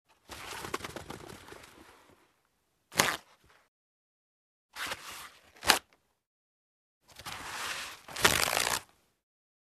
Звук отрывания листов и разматывания туалетной бумаги вариант 3